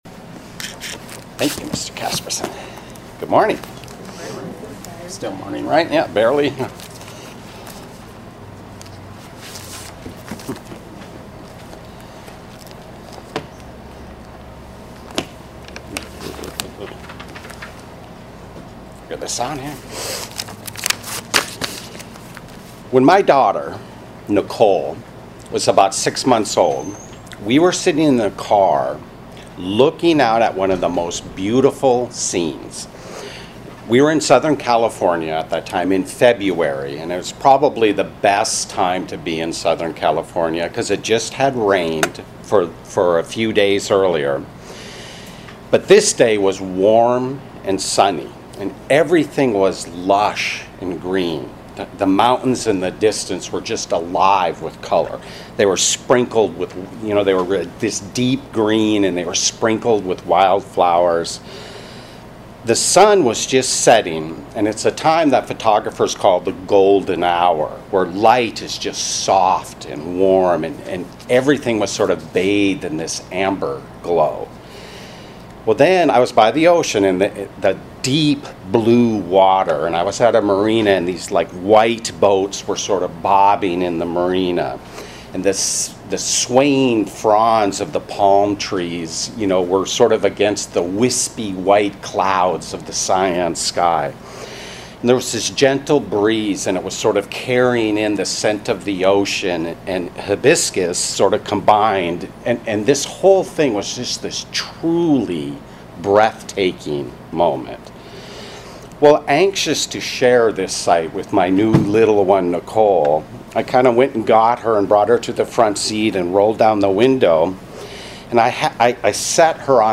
Given in Hartford, CT